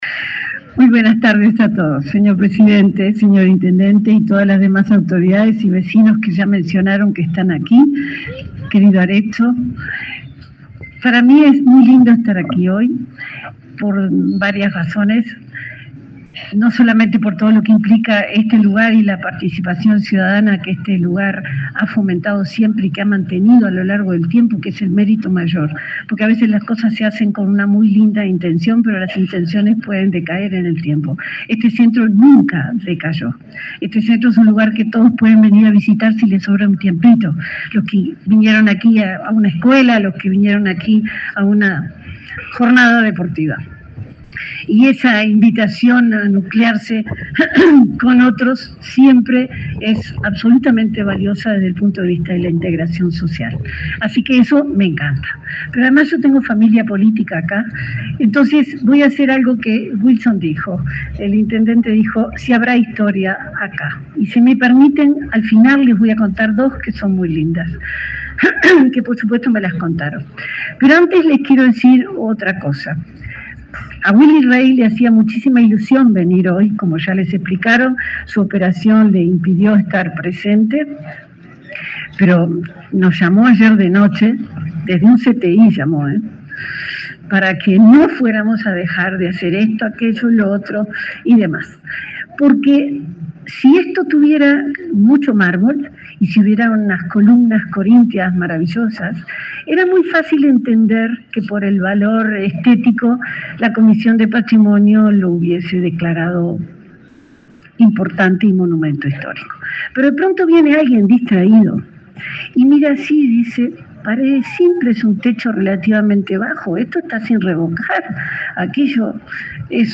Palabras de la subsecretaria de Educación y Cultura
La subsecretaria de Educación y Cultura, Ana Ribeiro, fue la oradora central, este jueves 27 en Tacuarembó, en el acto en que se declaró al centro del